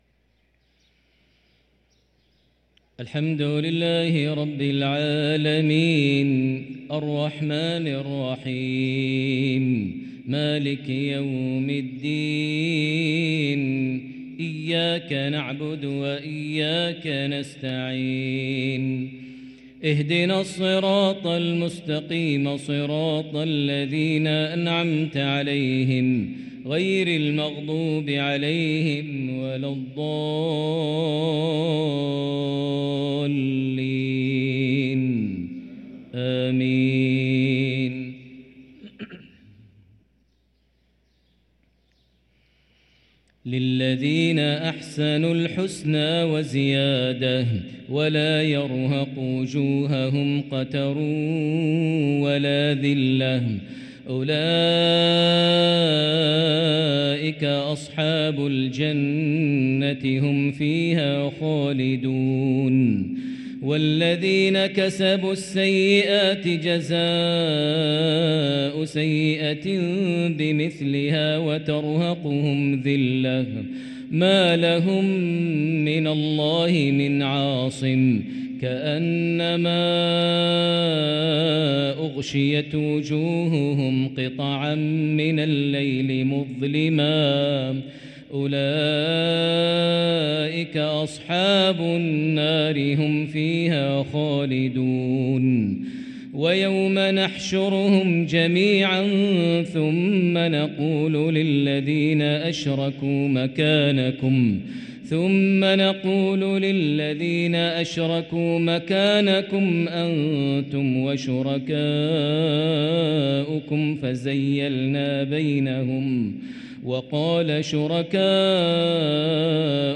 صلاة العشاء للقارئ ماهر المعيقلي 5 شعبان 1444 هـ
تِلَاوَات الْحَرَمَيْن .